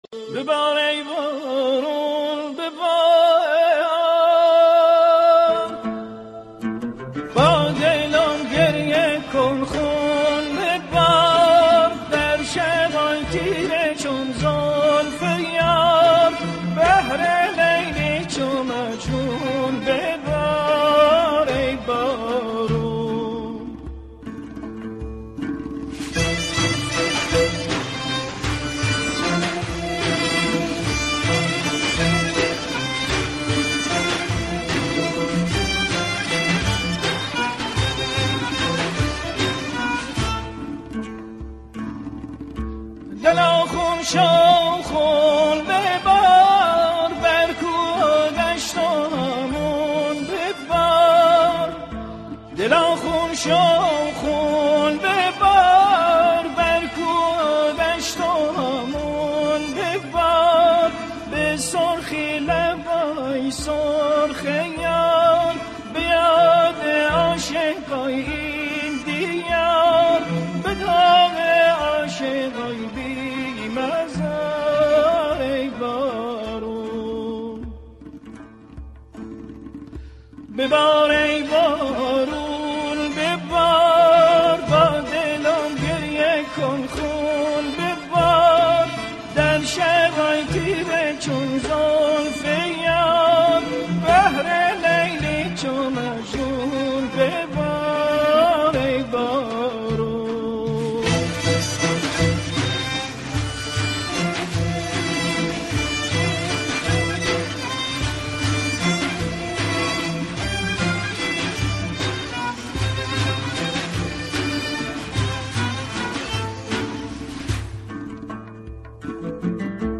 ژانر: سنتی